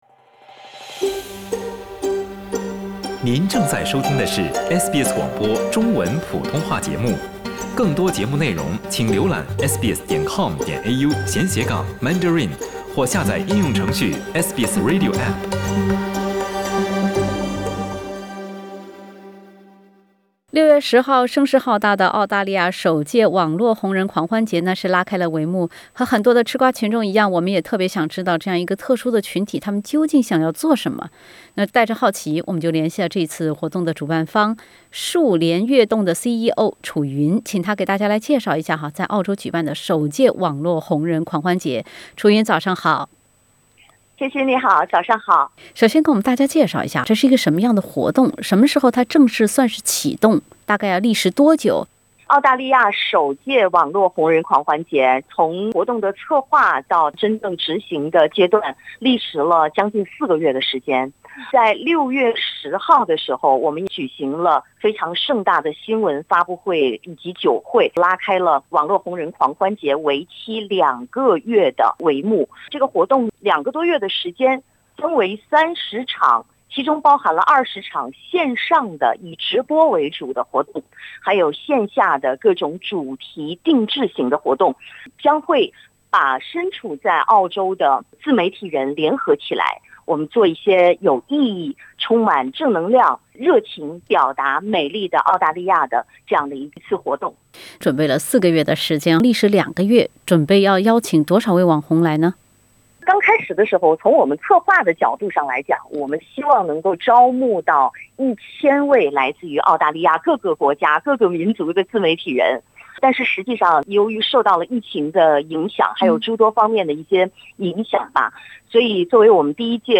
近千名澳大利亞網紅齊聚悉尼，成立“澳大利亞自媒體人協會”，探尋如何將自媒體變現。(點擊封面圖片，收聽完整對話）